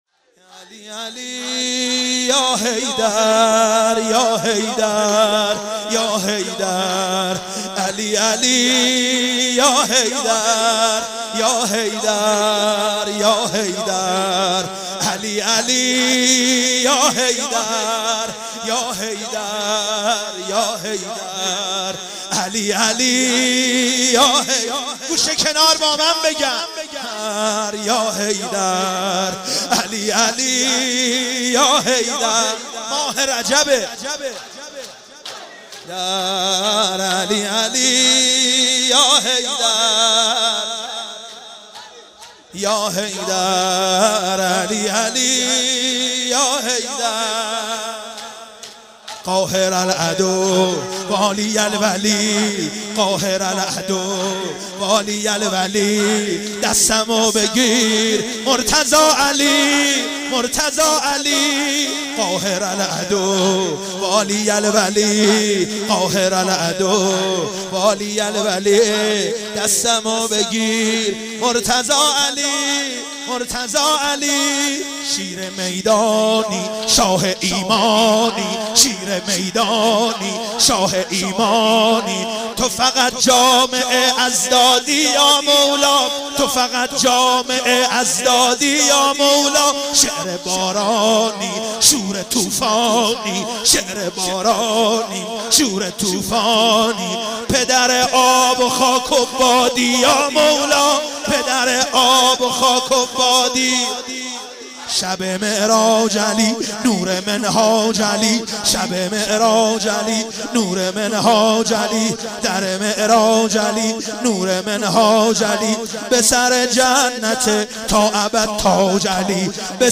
مناسبت : شهادت امام علی‌النقی الهادی علیه‌السلام
قالب : شور